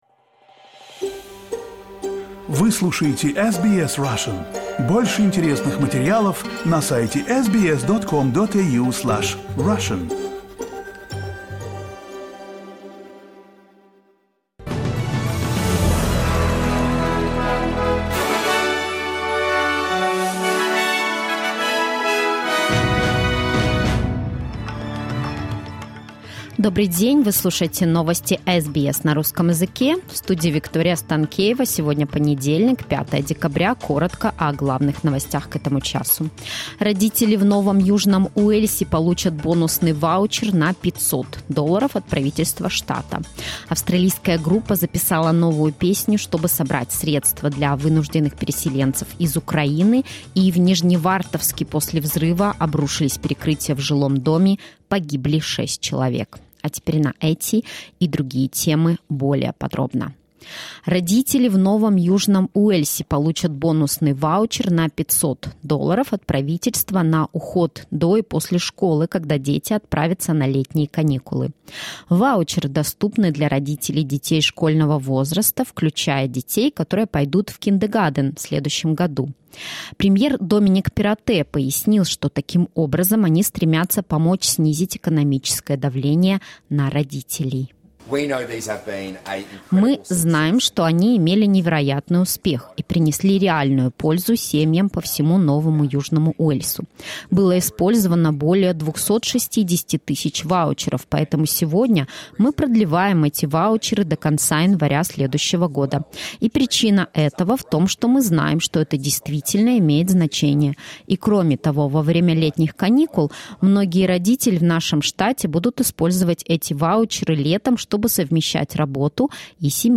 SBS news in Russian — 05.12.2022